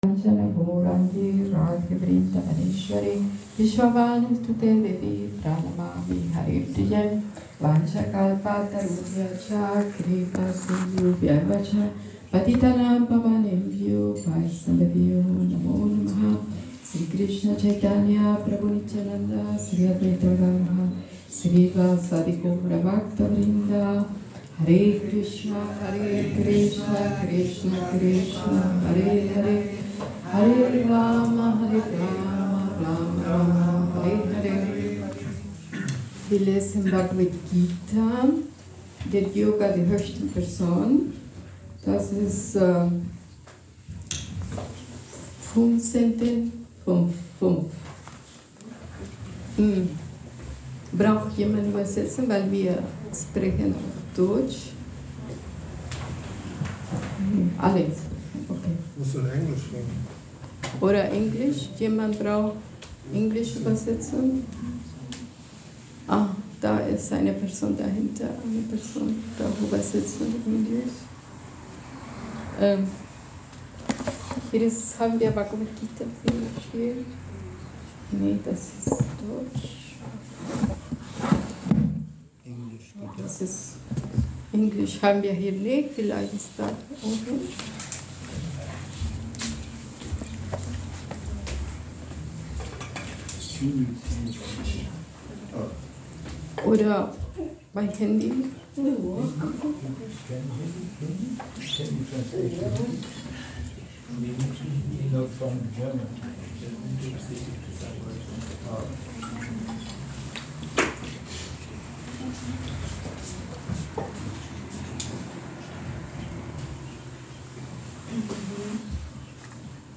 Unsere Verbindung zu Krishna – Vortrag zu Bhagavad Gita 15.5